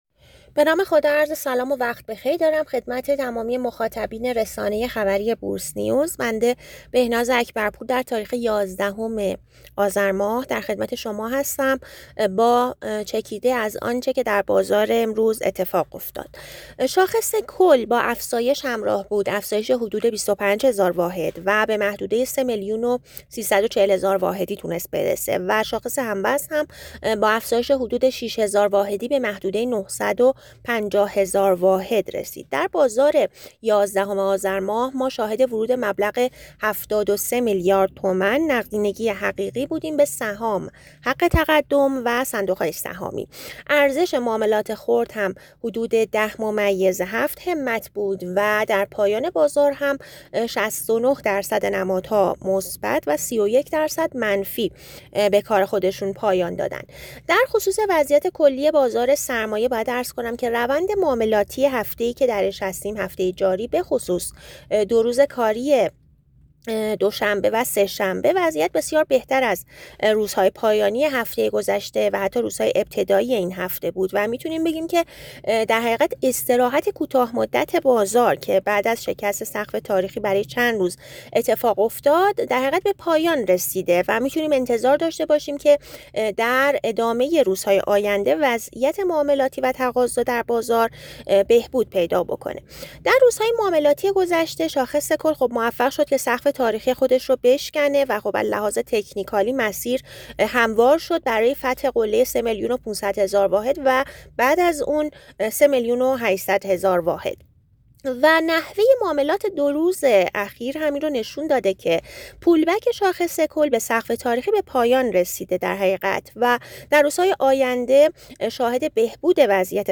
کارشناس بازار سرمایه در گفت‌و‌گو با بورس‌نیوز